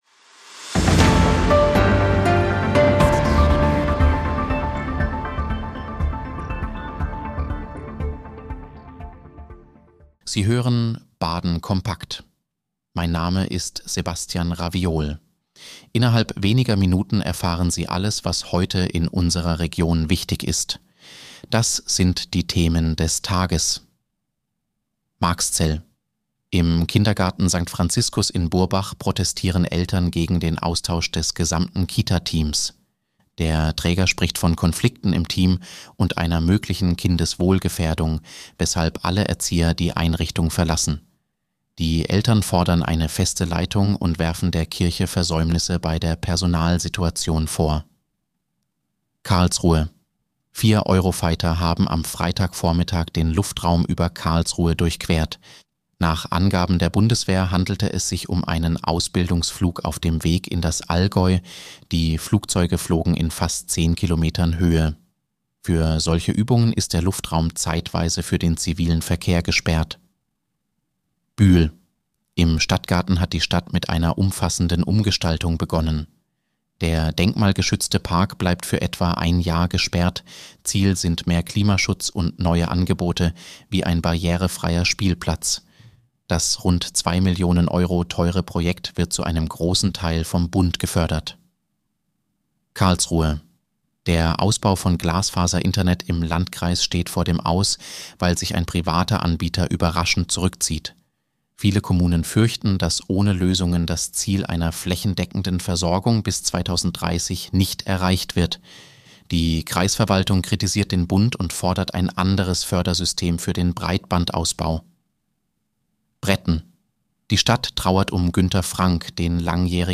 Nachrichtenüberblick: Elternprotest gegen Kita-Teamwechsel in Marxzell